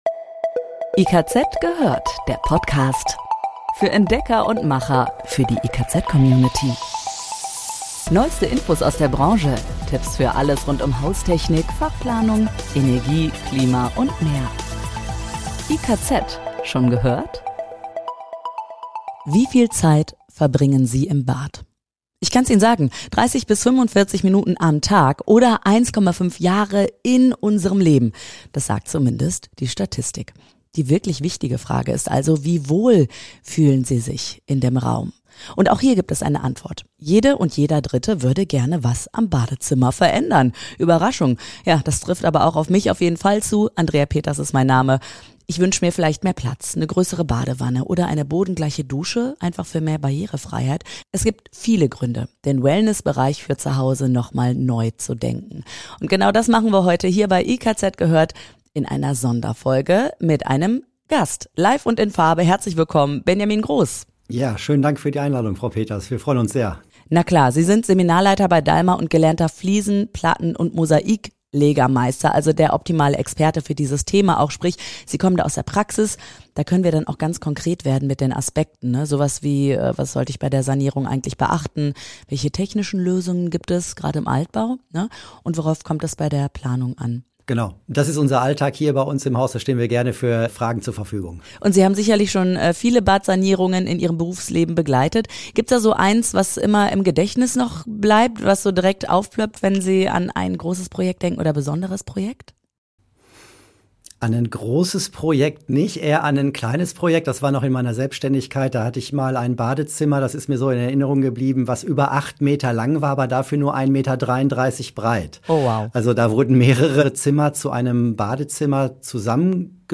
LIVE Expertengespräch: Gut geplant, besser saniert - FAQs der Badsanierung ~ IKZ gehört Podcast